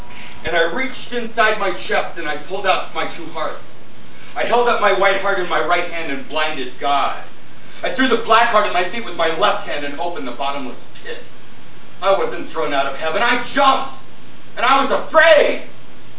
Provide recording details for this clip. performed by him in Denver in 1996.